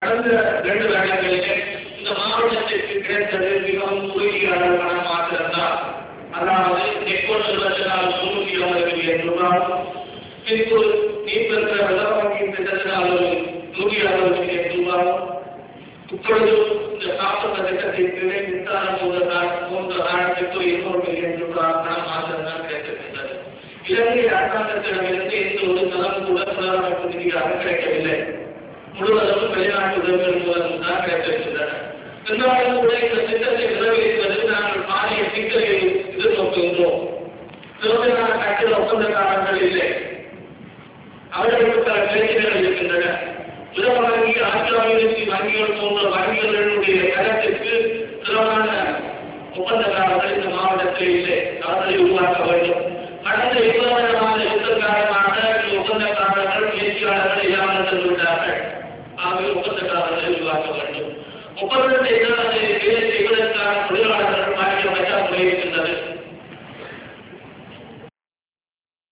TamilNet releases relevant parts of GA's speech in audio
TamilNet publishes the relevant parts of the speech by Government Agent of Jaffna at a meeting in Chavakachcheri on 27 December 2003 where he asserted that the Sri Lankan government “did not spend a red cent” on rehabilitation in the northern peninsula in the last two years.